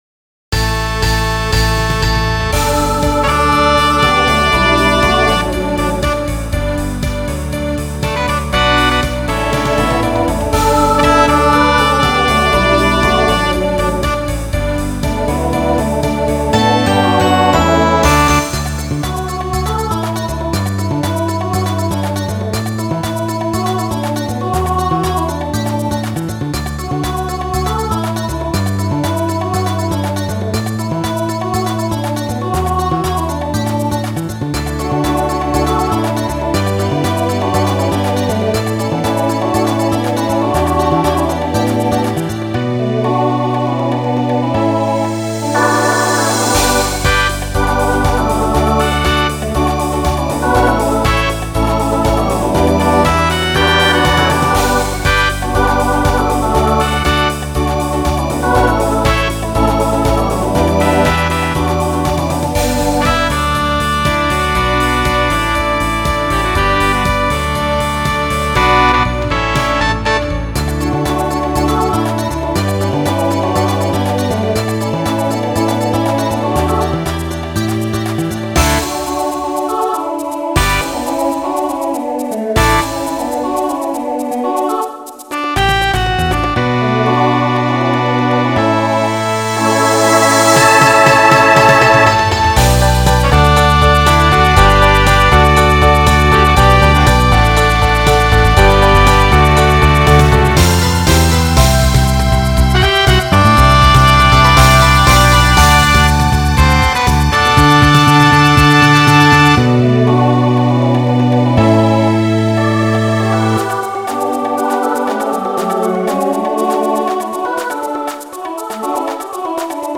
Voicing SATB Instrumental combo Genre Folk , Rock